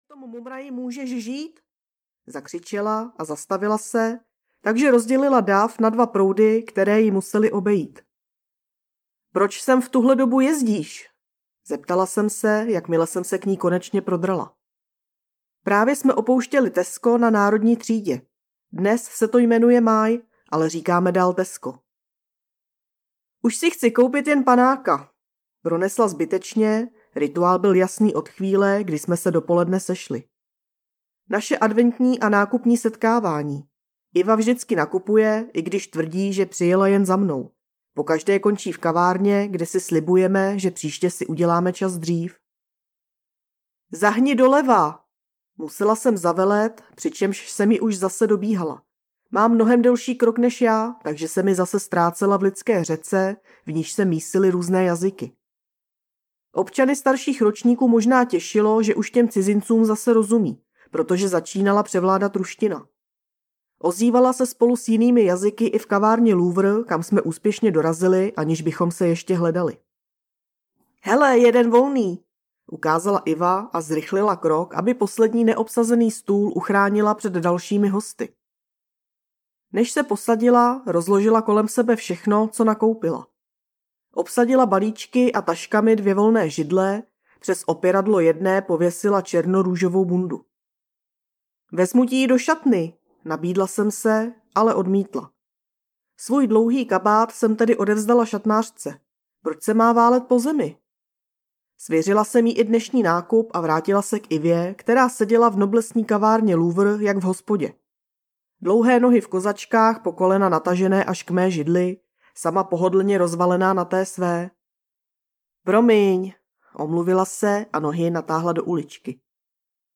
Co má společného malá nožka s Kámasútrou audiokniha
Ukázka z knihy